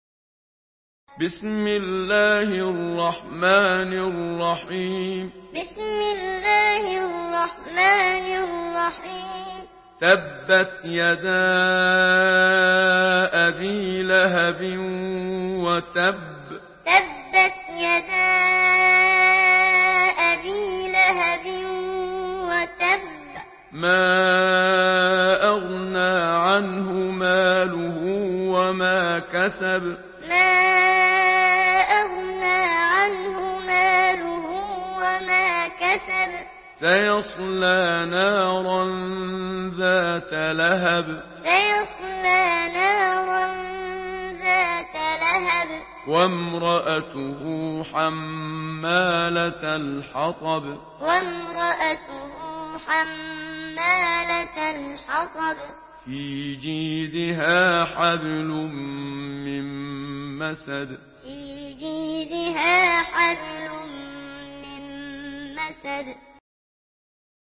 Surah Al-Masad MP3 by Muhammad Siddiq Minshawi Muallim in Hafs An Asim narration. Listen and download the full recitation in MP3 format via direct and fast links in multiple qualities to your mobile phone.